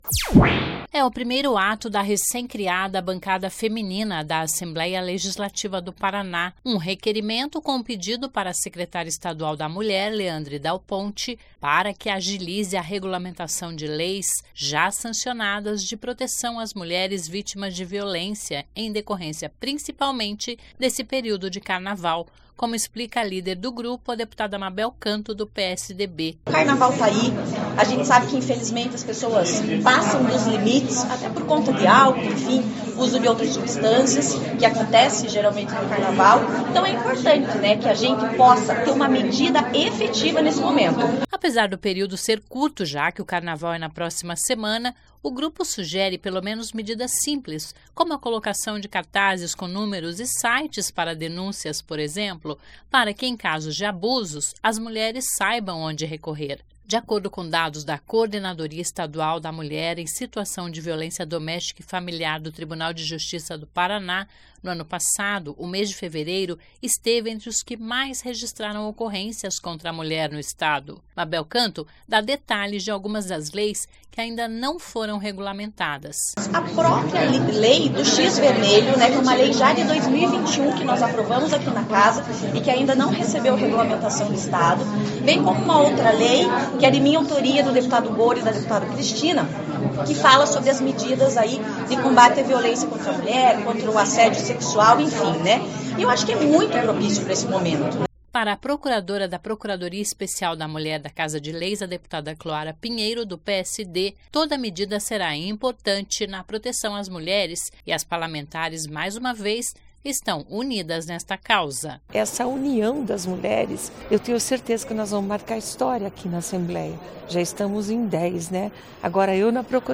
Um requerimento com um pedido para a  secretária Estadual da Mulher, Leandre Dal Ponte,  para que agilize a  regulamentação de  leis já sancionadas de proteção às mulheres vítimas da violência, em decorrência, principalmente, desse período de carnaval,  como explica a líder do grupo, a deputada Mabel canto (PSDB).
Mabel Canto dá detalhes de algumas das leis, que ainda não foram regulamentadas.
Para a procuradora da Procuradoria Especial da Mulher da Casa, a deputada Cloara Pinheiro (PSD), toda medida será importante na proteção às mulheres e as parlamentares, mais uma vez, estão unidas nesta causa.